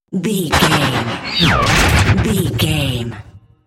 Sci fi gun shot hit whoosh
Sound Effects
heavy
intense
dark
aggressive